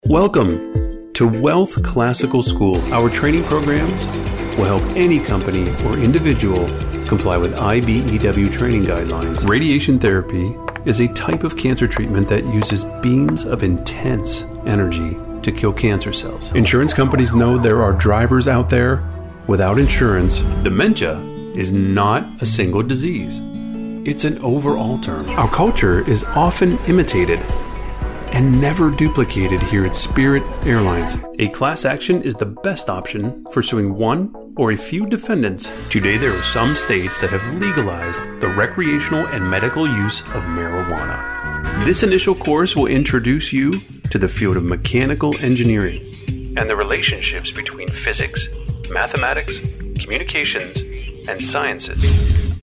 Sizzle Narration Demo